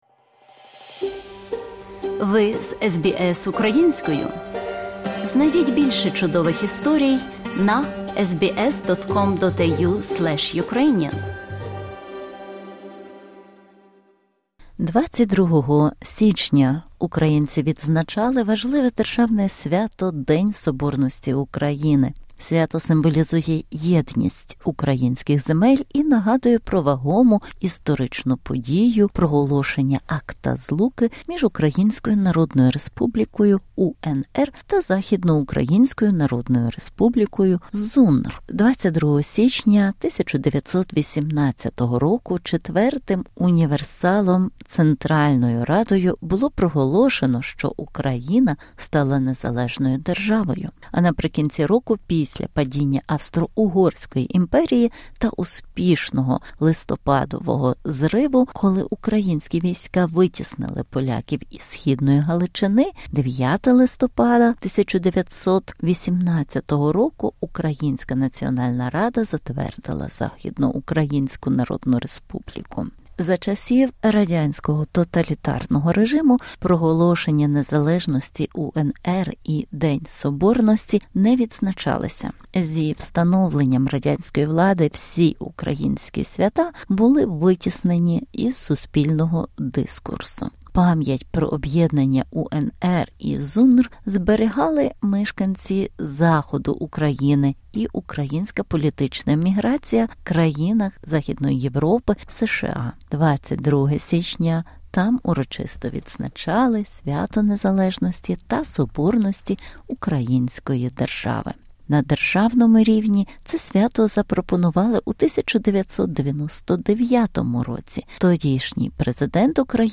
У цій добірці інтерв’ю представники Мельбурна, Сіднея, Брісбена, обговорюють важливість Дня Соборності України, який відзначається щорічно 22 січня.